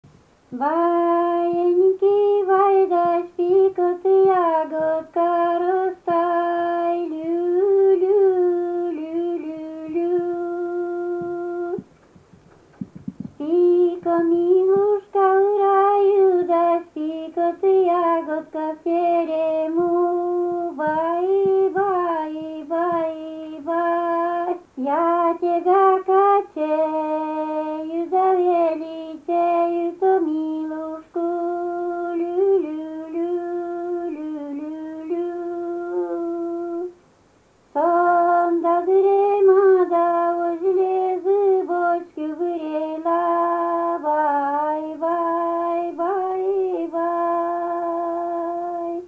kolybel.mp3